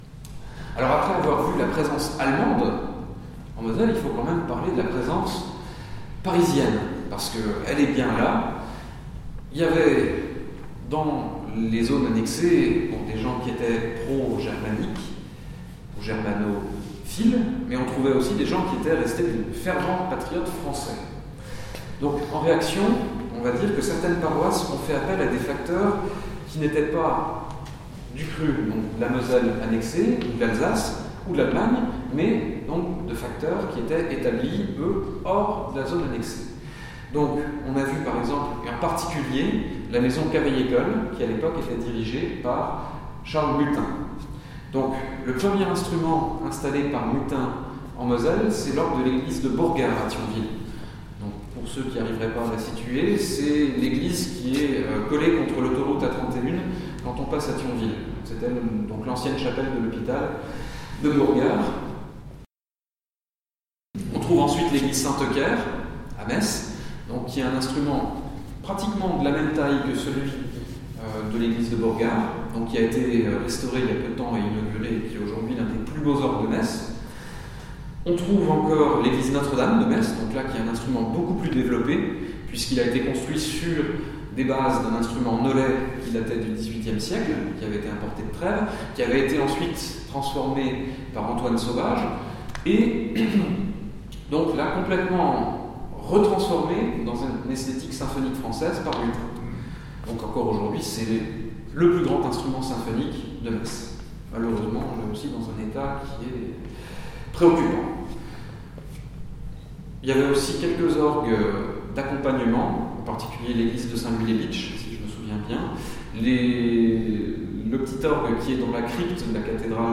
Extraits de la conférence